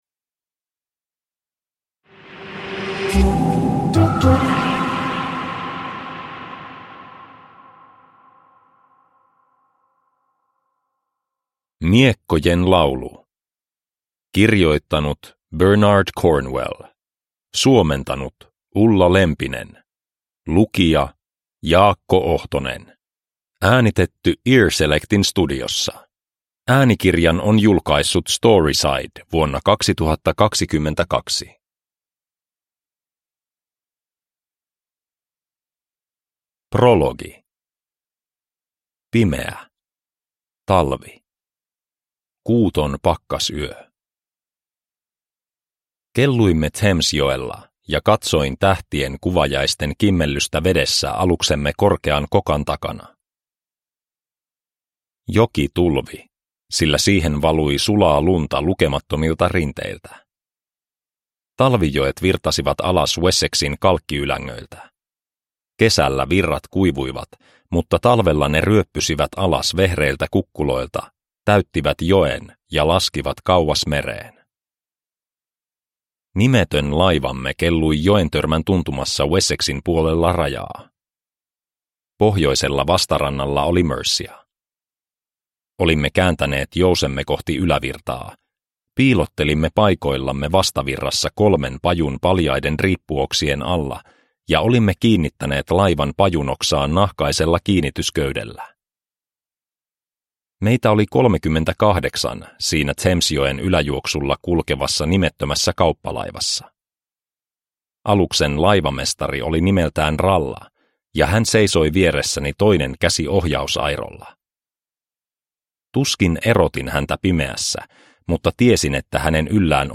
Miekkojen laulu – Ljudbok – Laddas ner